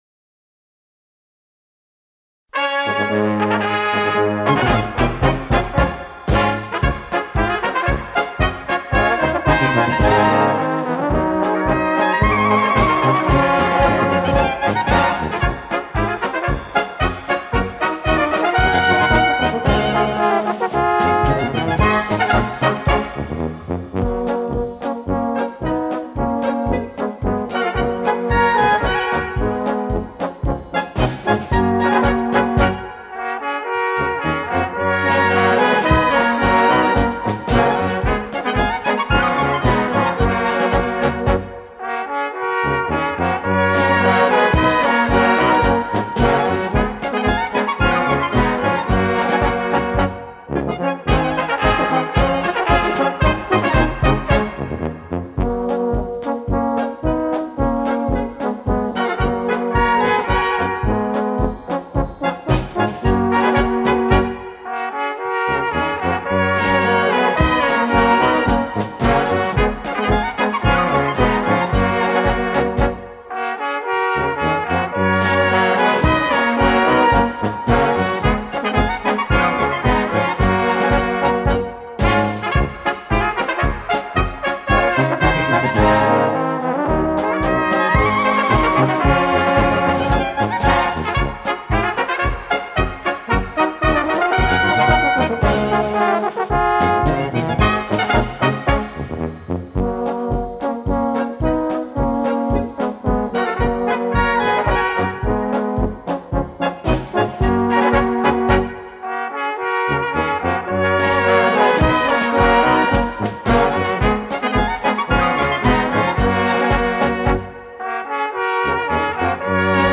Volkslied/Polka